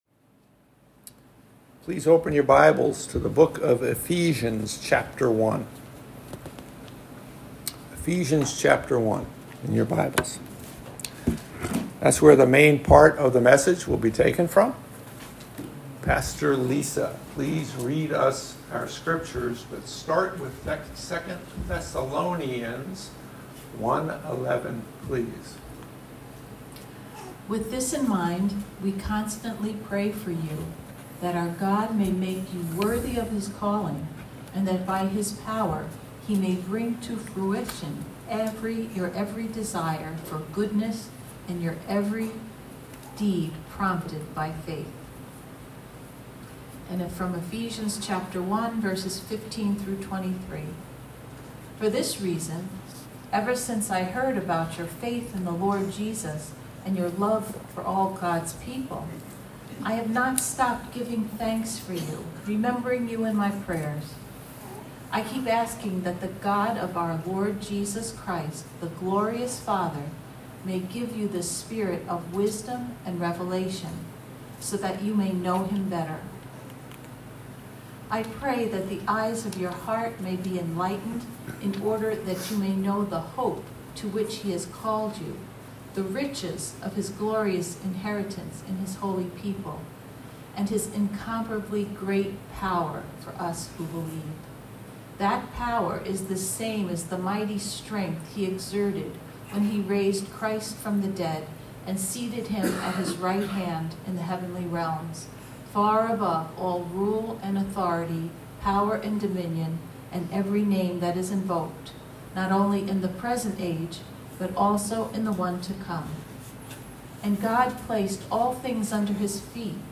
Sermon Mp3s Archives - Living Water Church